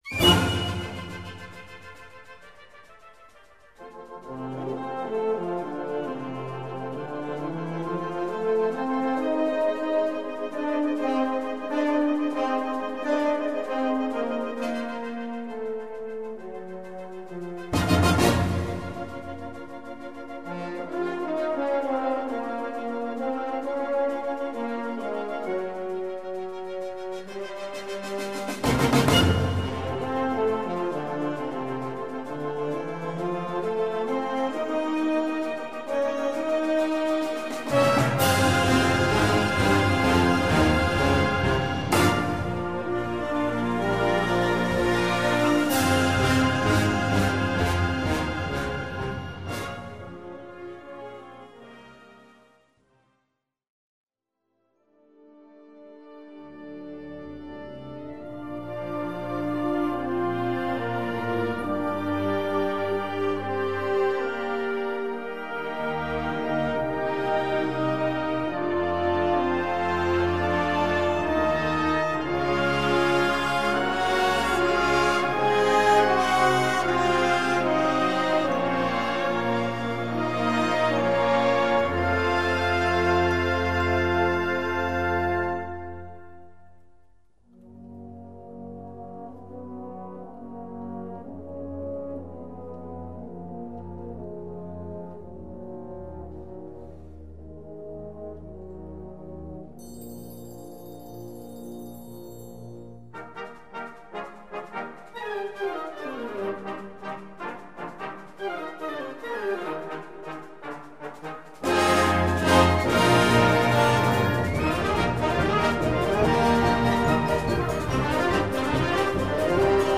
Gattung: Fantasie
Besetzung: Blasorchester
mit einer frischen und mitreißenden Tonsprache